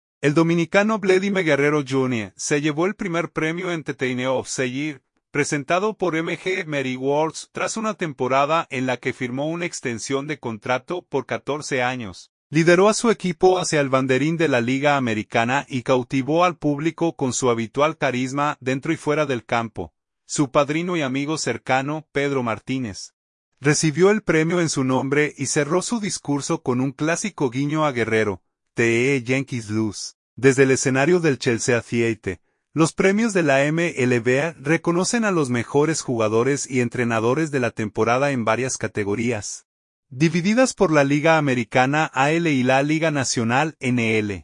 Su padrino y amigo cercano, Pedro Martínez, recibió el premio en su nombre y cerró su discurso con un clásico guiño a Guerrero: “Theeee Yankees lose”, desde el escenario del Chelsea Theater.